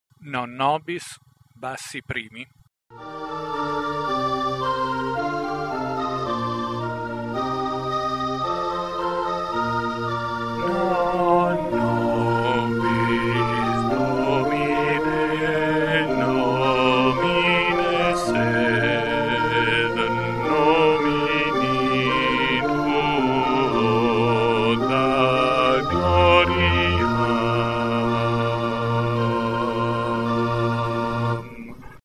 Non nobis - Bassi 1+ Base.mp3